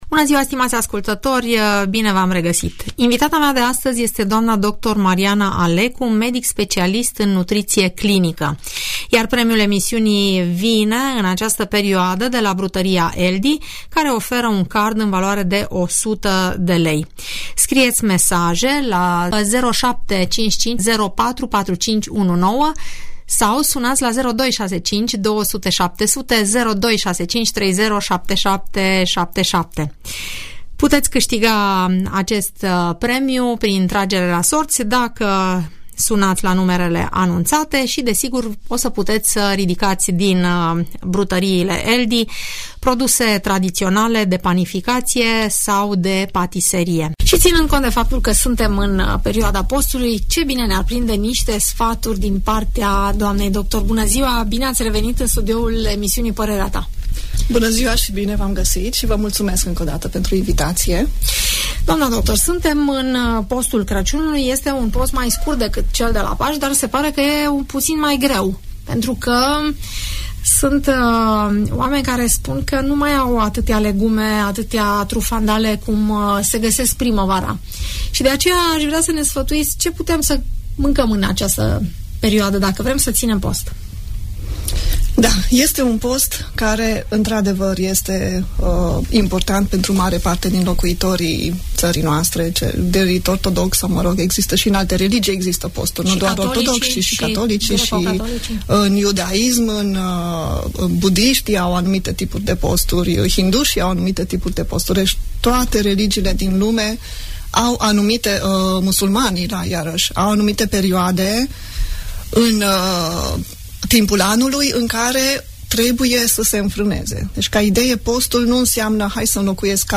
specialistă în nutriție, răspunde întrebărilor pe această temă.